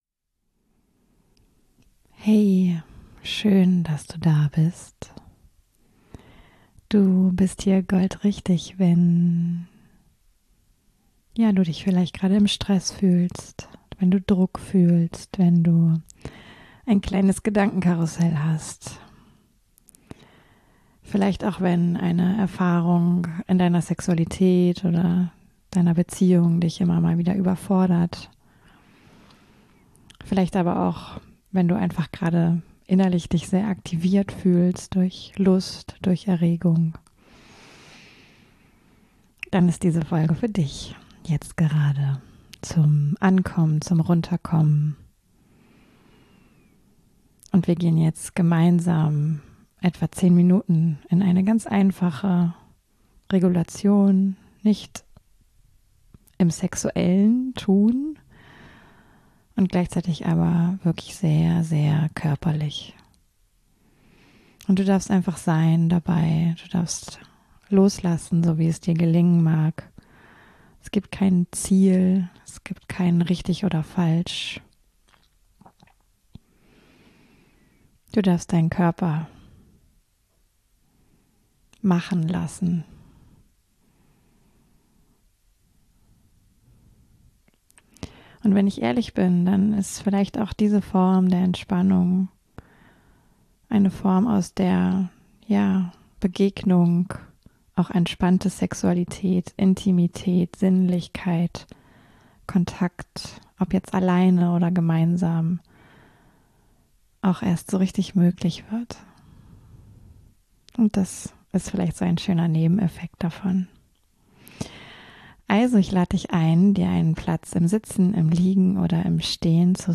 In diesem Mittwochs-Moment begleite ich dich durch eine 10-minütige Regulationsübung, die dich zurück in deinen Körper holt, wenn alles gerade viel - oder (zu) viel - ist. Für Momente von Stress, Druck, Gedankenspiralen, emotionaler (Über)Forderung oder auch intensiver innerer Aktivierung durch Lust...